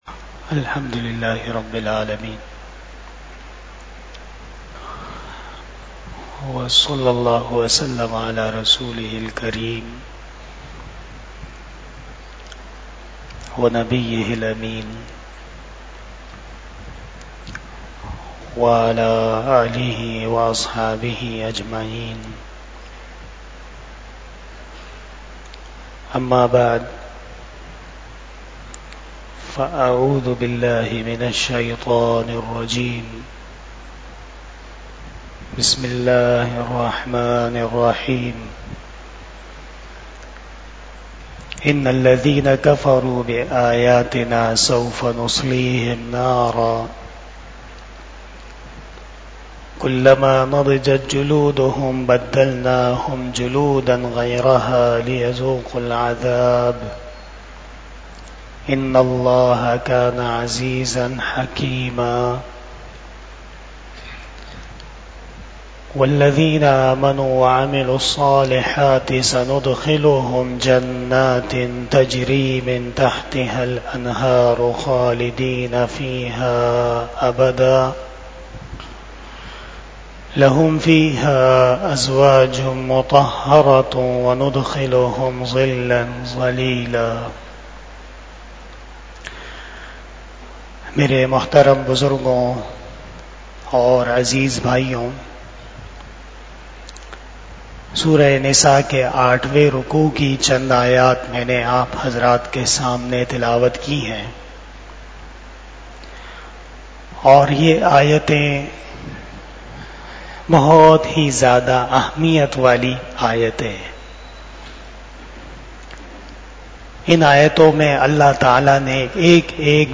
24 Shab E Jummah Bayan 25 July 2024 (19 Muharram 1446 HJ)
بیان شب جمعۃ المبارک